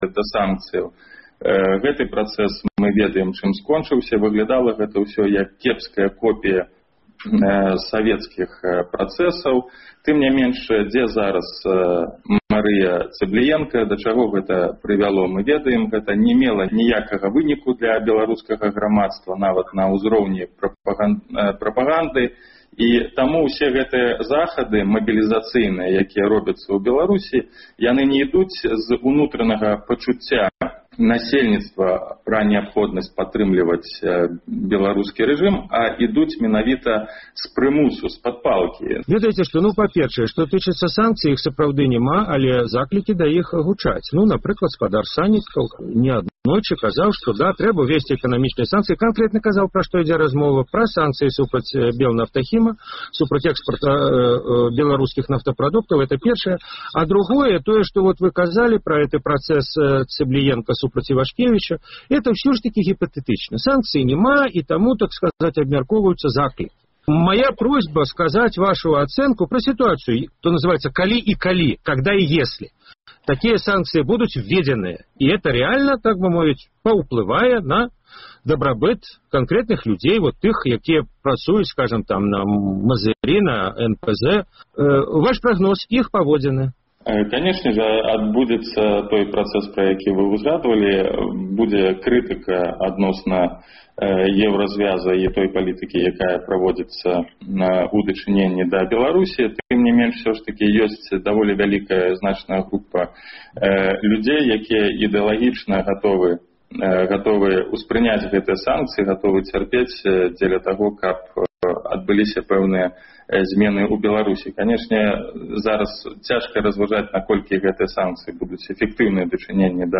Штотыднёвы круглы стол экспэртаў і аналітыкаў на актуальную тэму.